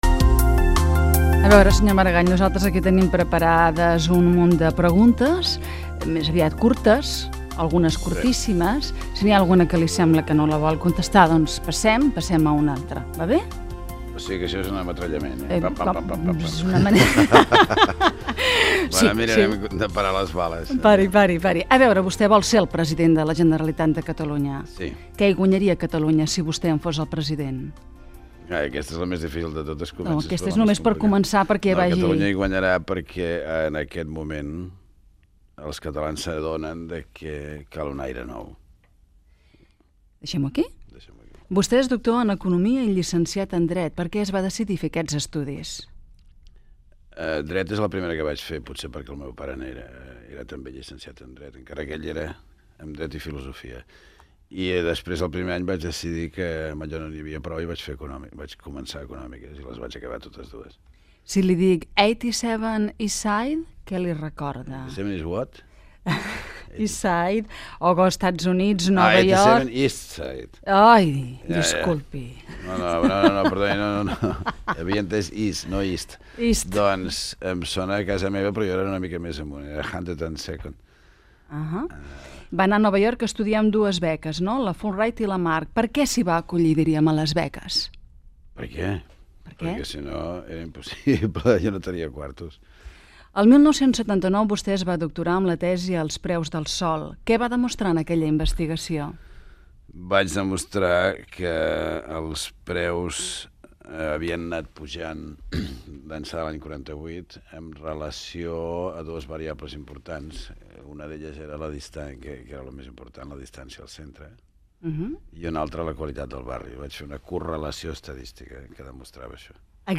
Fragment d'una entrevista al candidat a president de la Generalitat Pasqual Maragall.